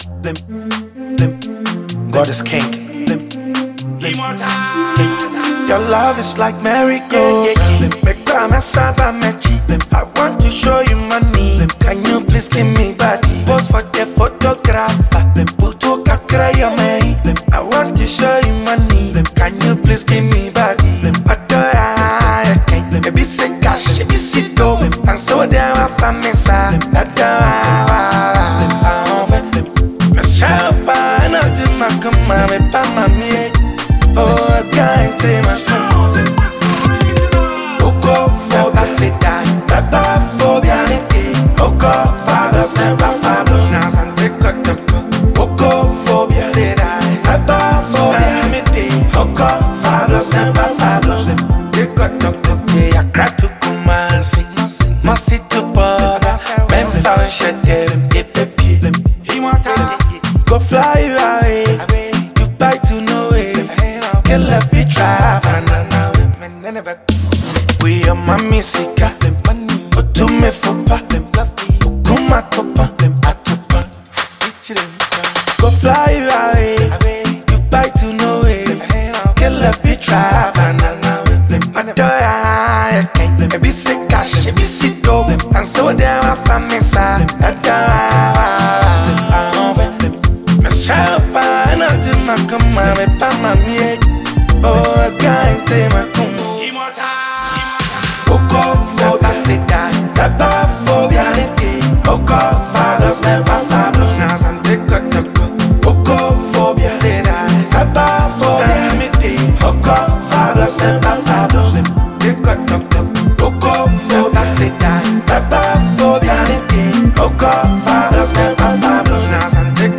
a Ghanaian rapper-cum-singer